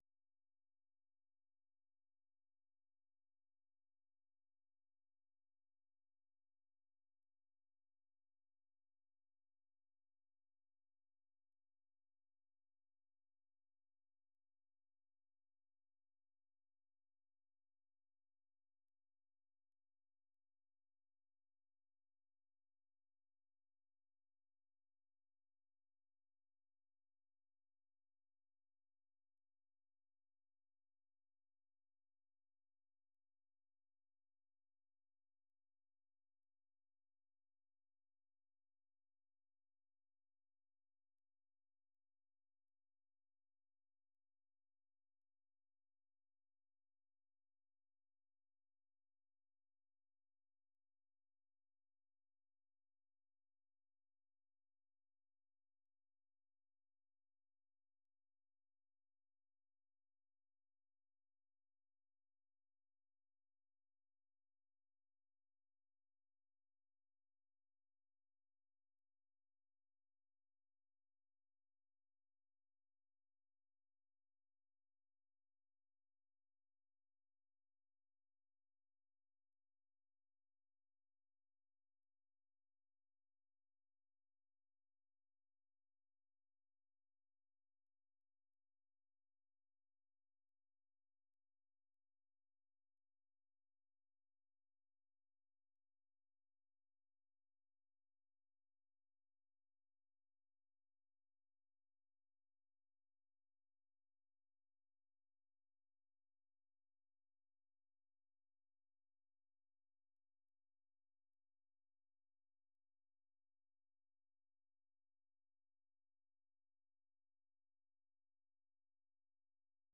생방송 여기는 워싱턴입니다 저녁